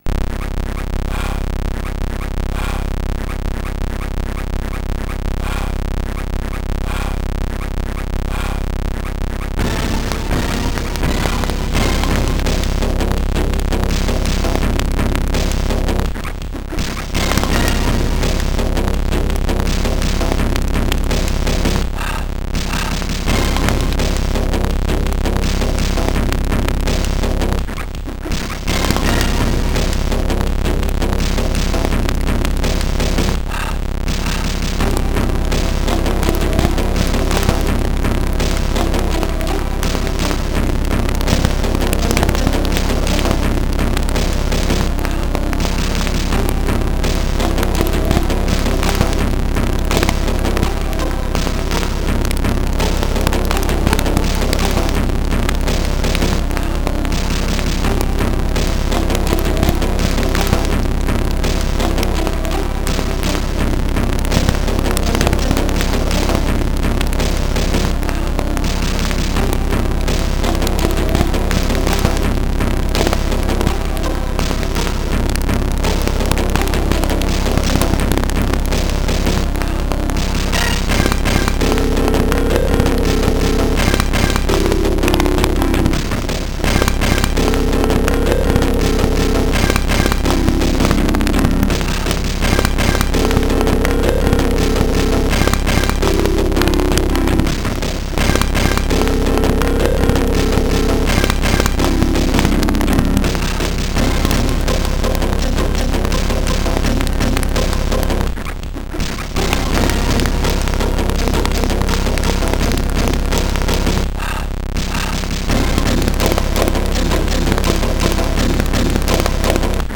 Game music recorded by: SNDH Recording project
Digital title music
This game features digital title music